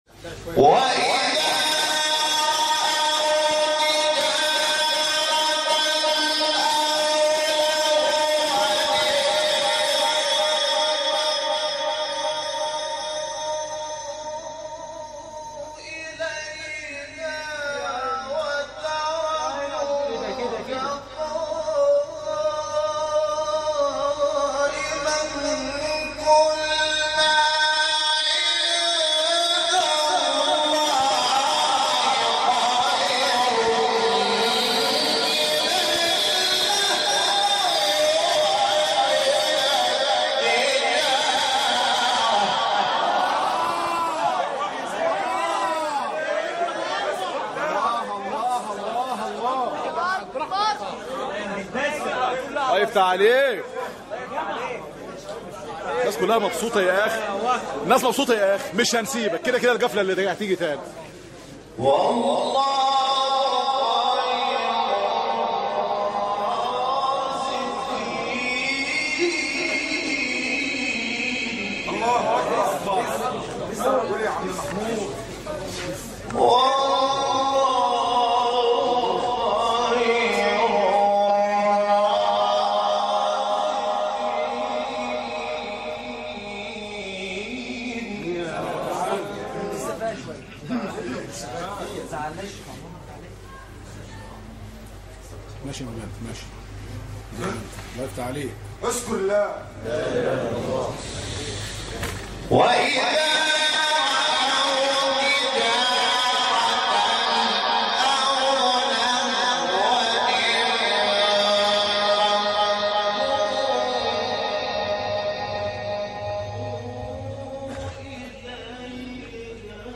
مقام : مرکب خوانی(رست * نهاوند * رست)